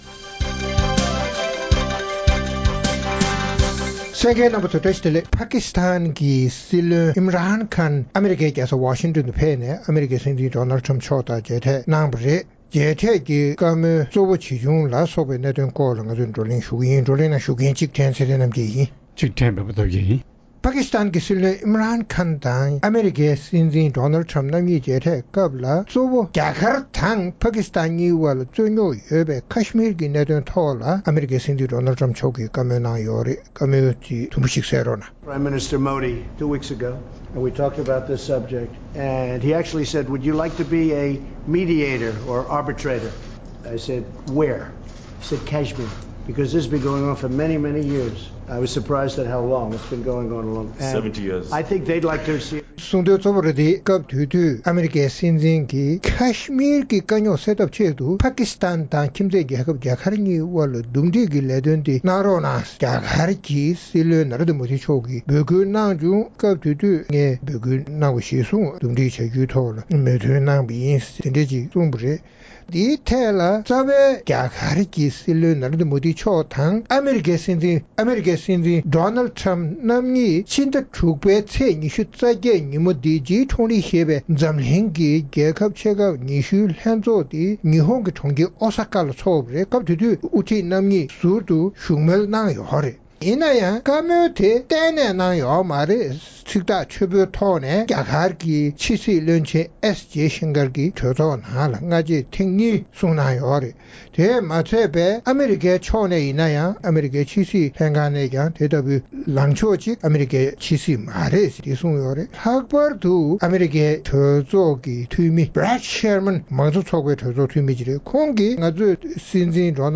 རྩོམ་སྒྲིག་པའི་གླེང་སྟེགས་ཞེས་པའི་ལེ་ཚན་ནང་།པཱ་ཀིསི་ཏཱན་(Pakistan) གྱི་སྲིད་བློན་ཨིམ་རཱན་ཁཱན་(Imran Khan) ཨ་མེ་རི་ཁར་ཉིན་གསུམ་རིང་གཞུང་དོན་ཕྱོགས་ཕེབས་གནང་སྟེ་ཨ་རིའི་སྲིད་འཛིན་དང་མཇལ་འཕྲད་གནང་བ་དེས་ཨཕ་གྷ་ནིསི་ཏཱན་(Afghanistan) ནང་ཞི་འགྲིག་དང་། དེ་མིན་ཀཤ་མཱིར་(Kashmir) གྱི་གནད་དོན་བཅས་ལ་ཤུགས་རྐྱེན་ཇི་ཐེབས་སོགས་ཀྱི་སྐོར་རྩོམ་སྒྲིག་འགན་འཛིན་རྣམ་པས་བགྲོ་གླེང་གནང་བ་གསན་རོགས་གནང་།